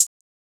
BWB UPGRADE3 Hi-HAT (10).wav